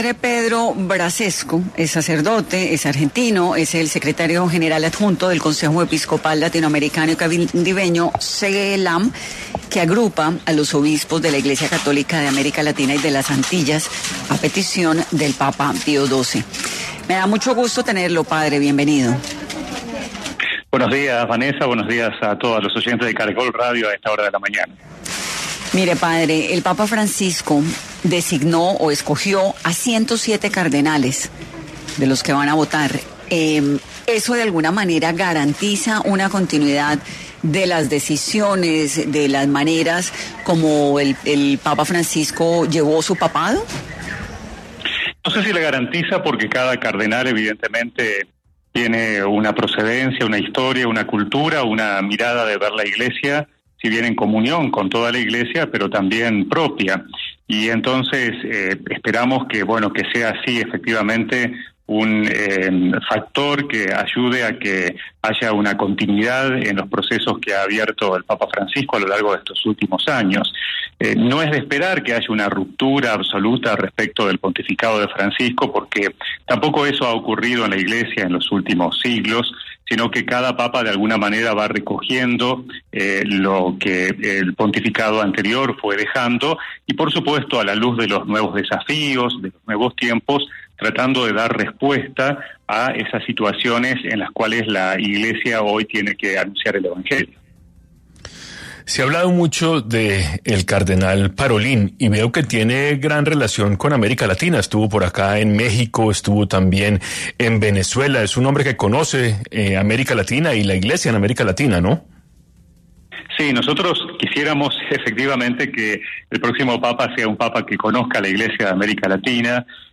En entrevista para 10AM